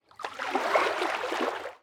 latest / assets / minecraft / sounds / mob / dolphin / swim2.ogg
swim2.ogg